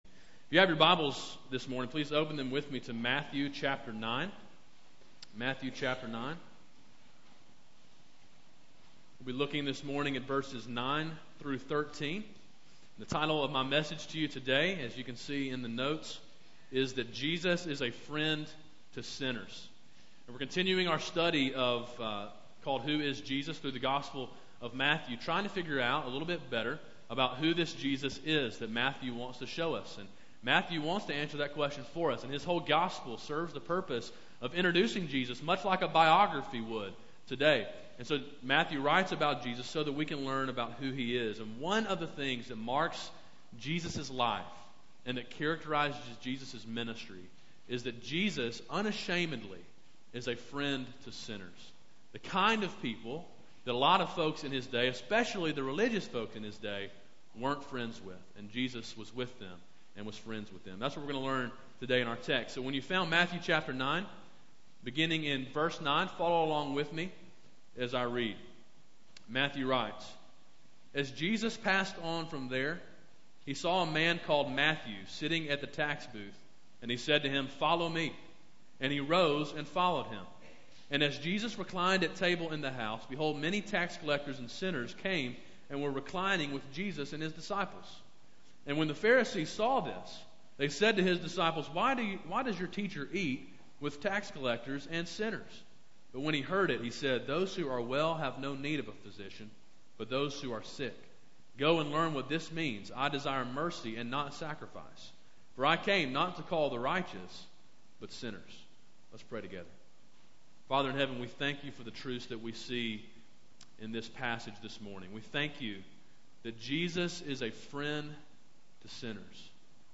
A sermon in a series entitled Who Is Jesus? A Study through the Gospel of Matthew.
march-4-2012-morning-sermon.mp3